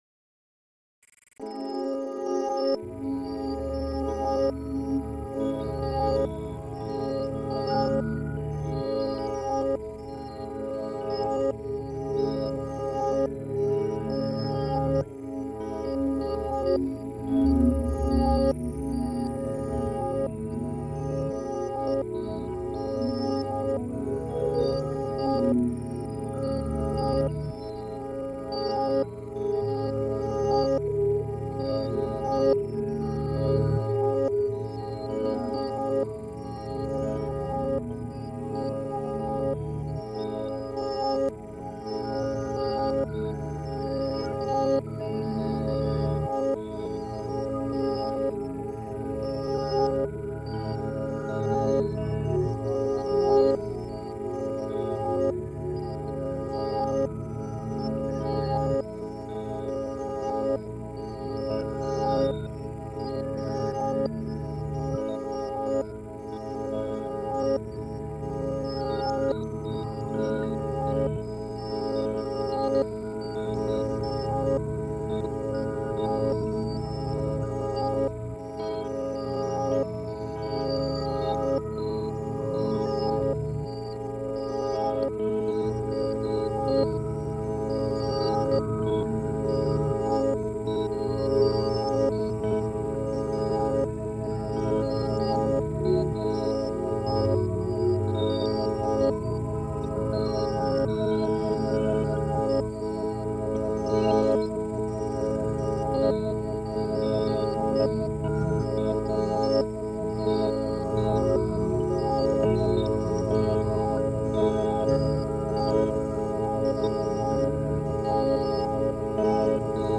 introspective and spatial ambient, of the dark kind
an intense drone